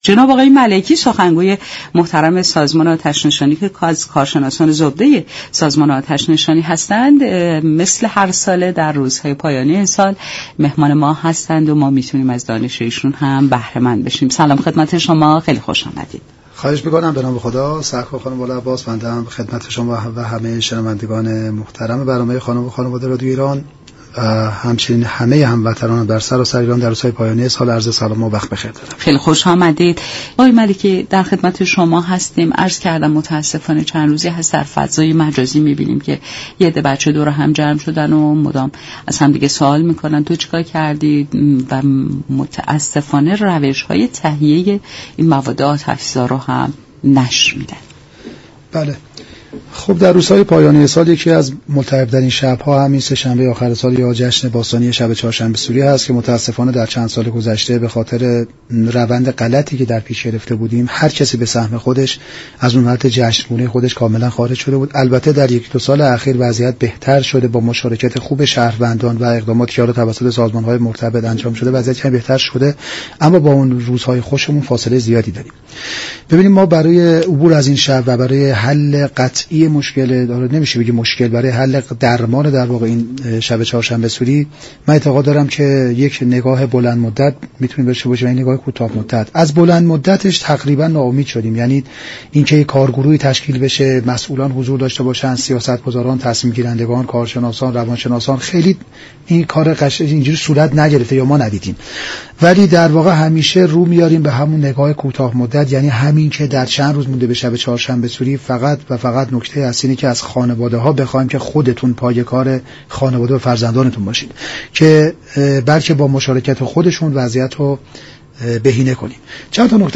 این گفت و گو را در ادامه باهم می شنویم.